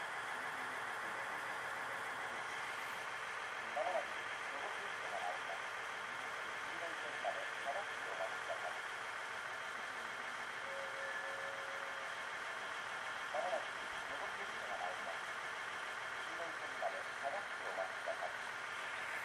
この駅では接近放送が設置されています。
２番線石巻線
接近放送普通　小牛田行き接近放送です。